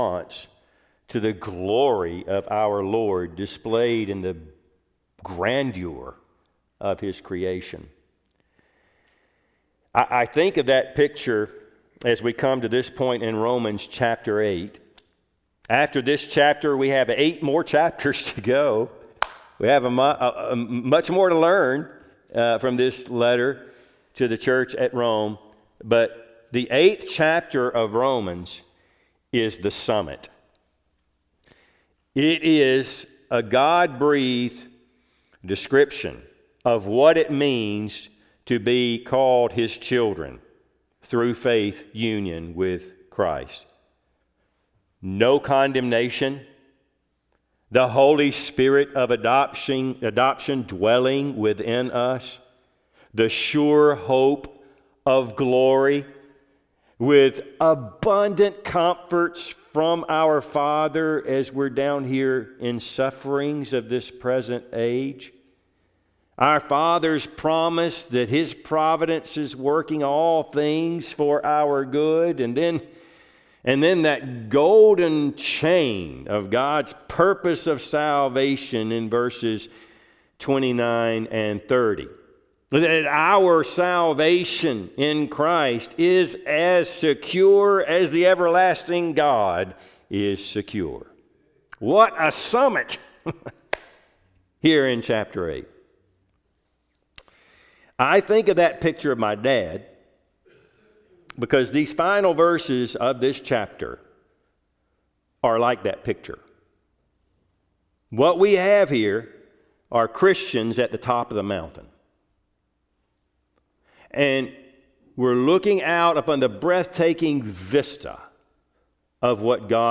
Passage: Romans 8:31-34 Service Type: Sunday AM